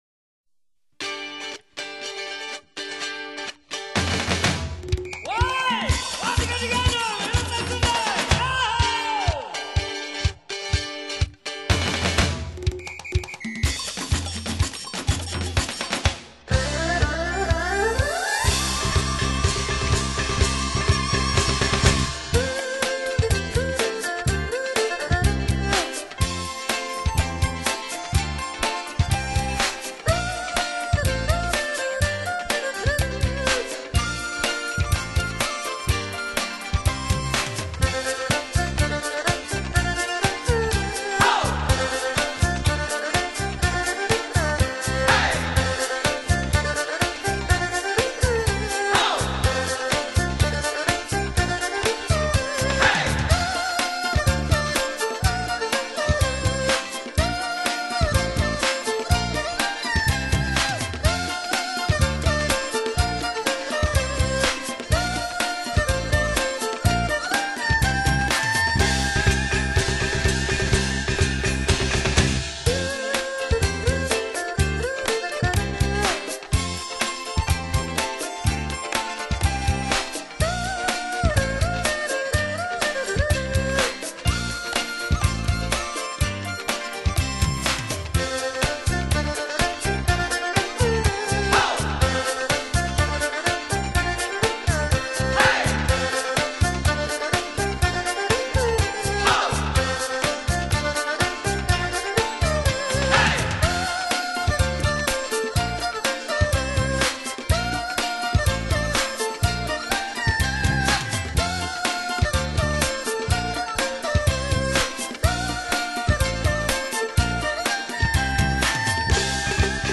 整張專輯以最酷的音組方式，傳達節慶中歡樂的氣氛，表現出人生High到最高點的得意！